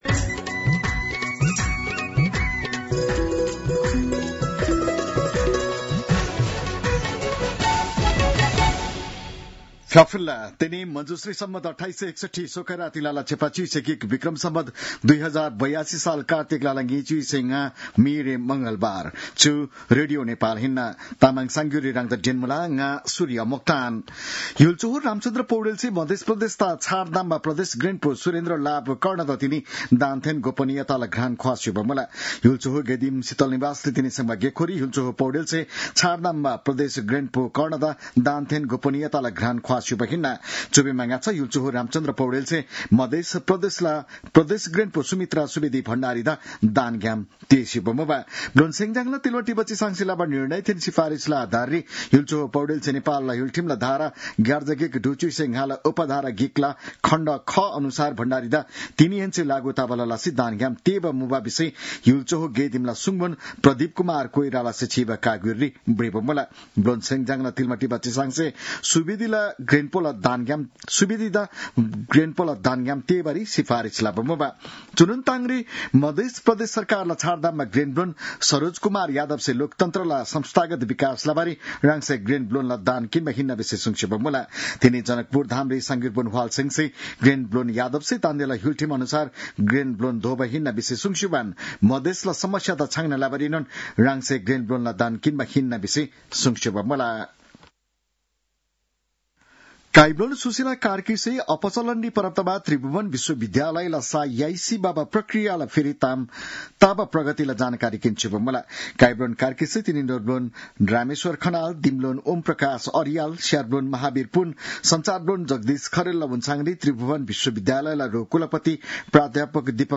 तामाङ भाषाको समाचार : २५ कार्तिक , २०८२